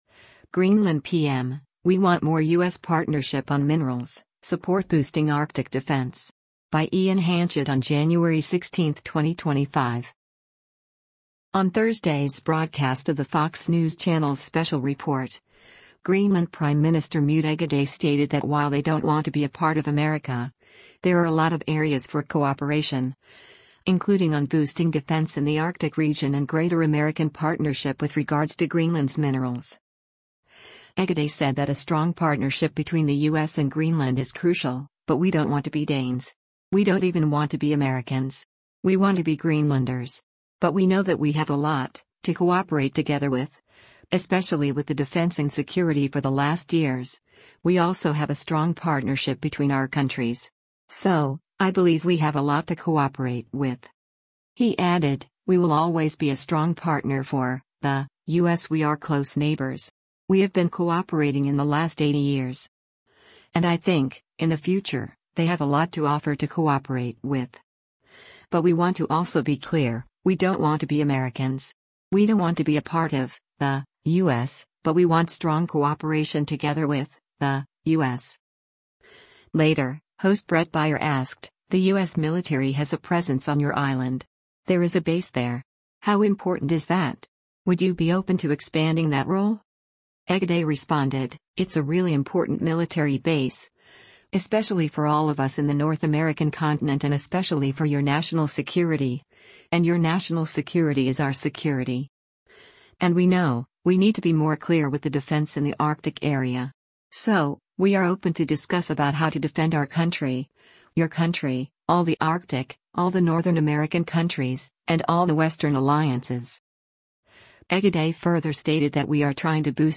On Thursday’s broadcast of the Fox News Channel’s “Special Report,” Greenland Prime Minister Múte Egede stated that while they don’t want to be a part of America, there are a lot of areas for cooperation, including on boosting defense in the Arctic region and greater American partnership with regards to Greenland’s minerals.